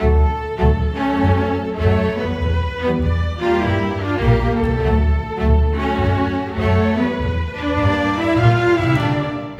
Rock-Pop 10 Strings 03.wav